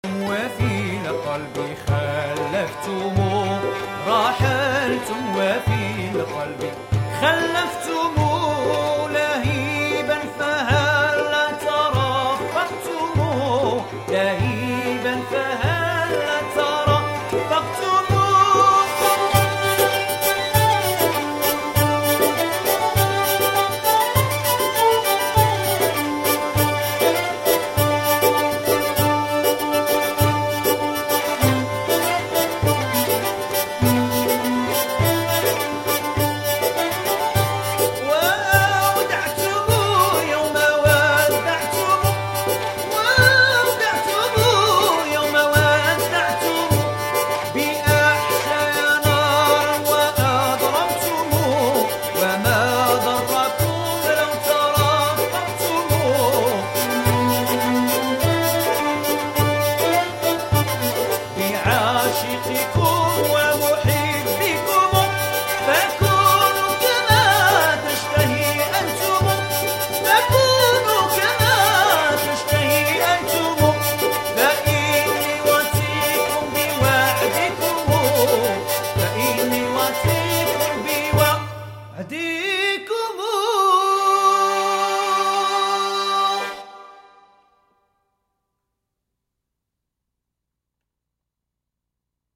Musiques d´al Andalus